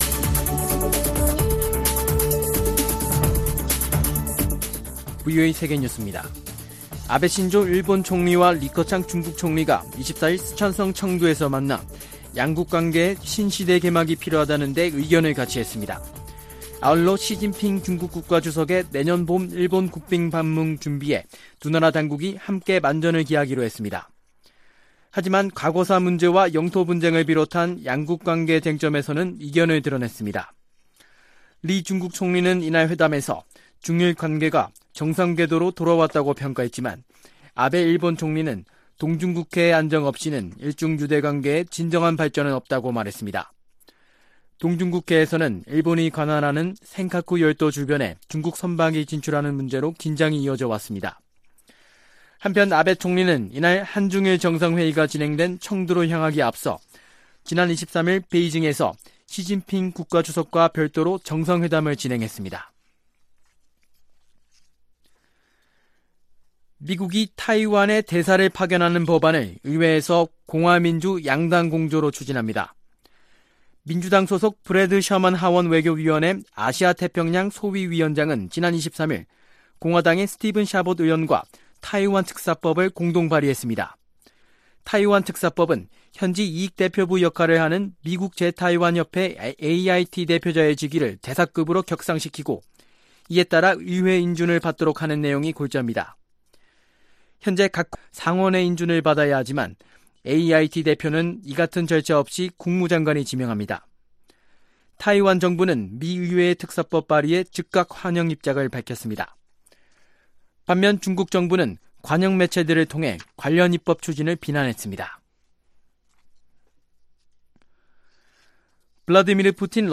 VOA 한국어 아침 뉴스 프로그램 '워싱턴 뉴스 광장' 2018년 12월 26일 방송입니다. 미국은 북한이 거론한 '크리스마스 선물'을 앞두고 고고도 무인 정찰기 등 전략자산을 대거 동원해 북한에 대한 감시 작전을 펼쳤습니다. 왕이 중국 외교담당 국무위원 겸 외교부장이 북한과의 비핵화 협상과 관련해 실질적인 조치를 취할 것을 미국에 촉구하고 나섰습니다.